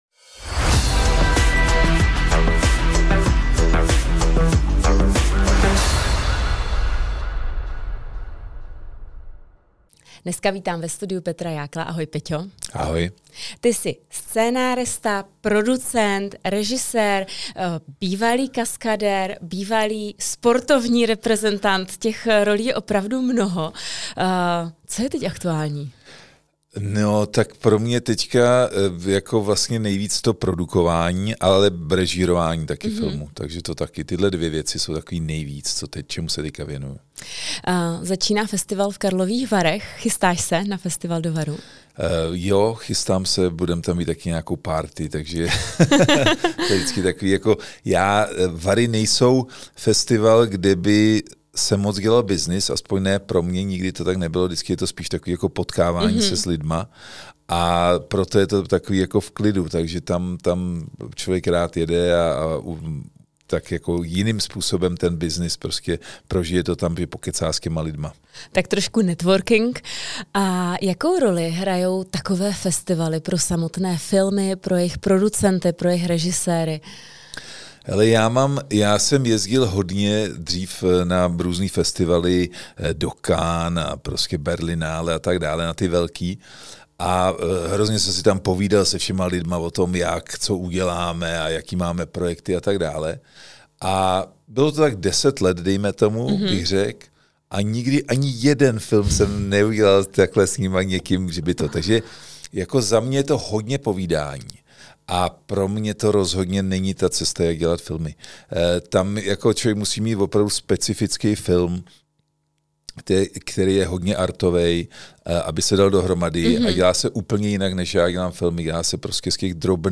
Tentokrát pozvání do studia přijal filmař Petr Jákl.